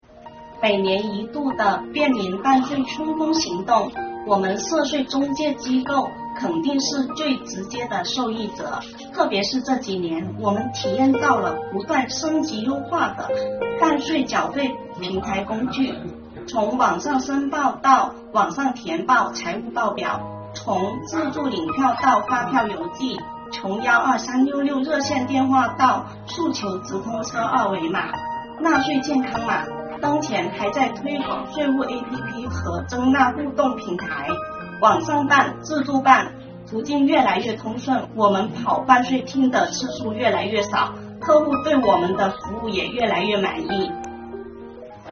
便民办税春风行动十周年系列访谈（第三期）